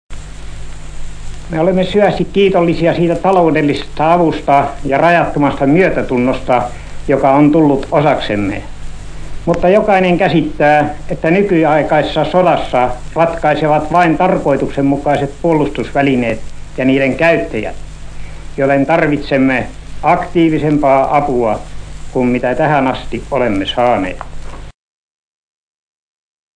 Die Rede ist aus dem Jahr 1919 und wurde nach den Präsidentschaftswahlen, in denen Ståhlberg zum ersten Präsidenten von Finnland gewählt worden, gehalten. In der Rede fordert Präsident Ståhlberg Integration und Aussöhnung nach den Ereignissen des Bürgerkrieges.